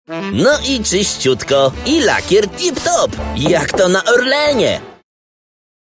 Commercieel, Diep, Natuurlijk, Vertrouwd, Vriendelijk
Flexible, energetic and charismatic voice.